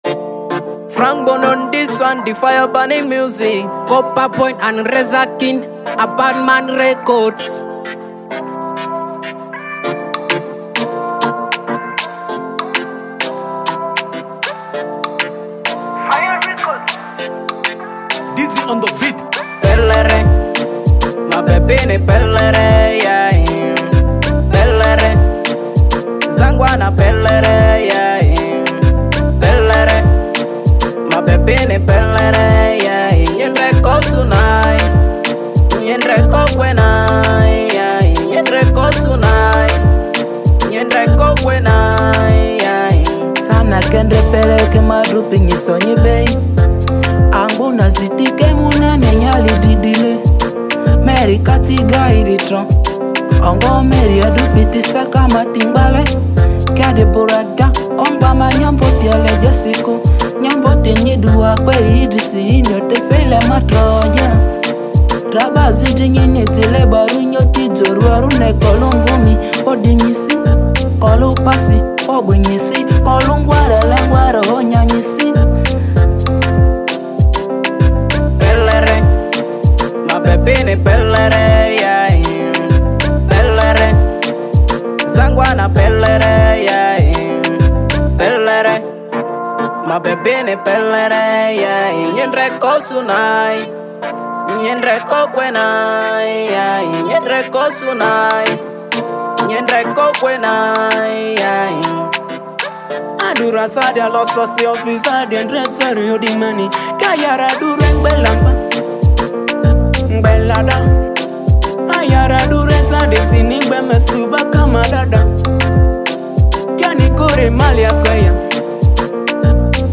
Genre: Dancehall